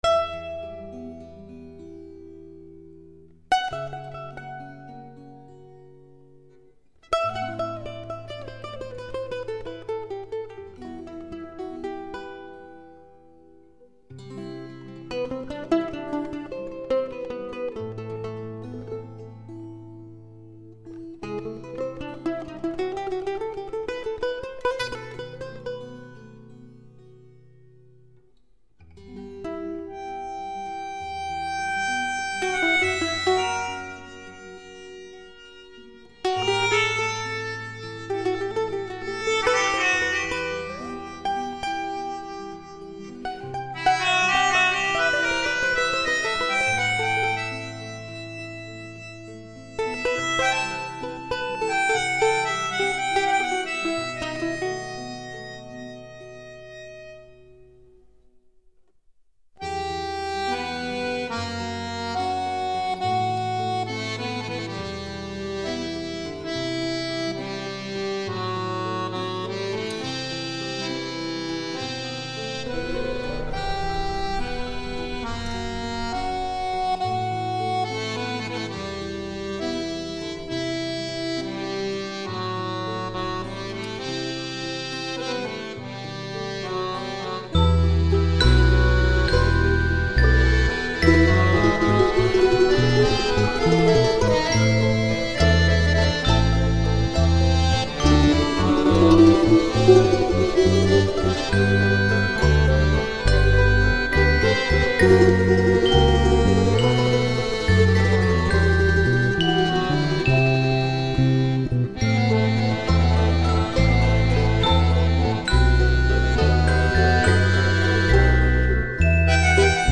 вальс